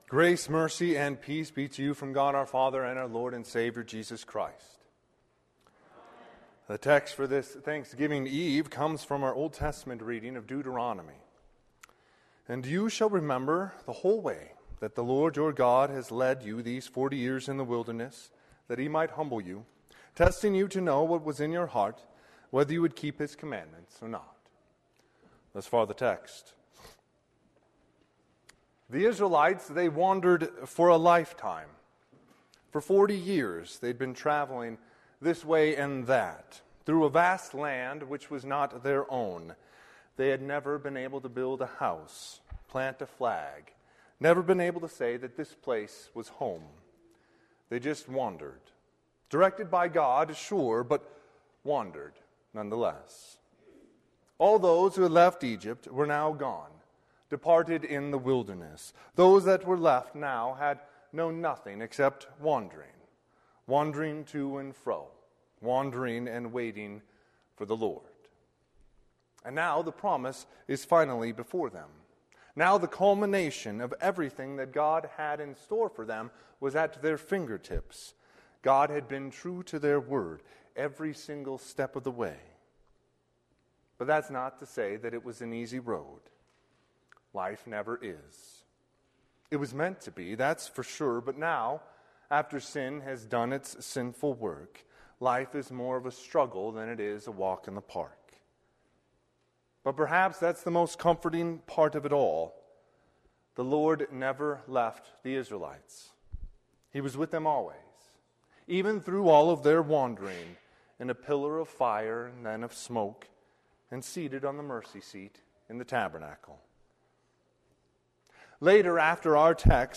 Sermon - 11/24/2021 - Wheat Ridge Lutheran Church, Wheat Ridge, Colorado
Thanksgiving Eve Service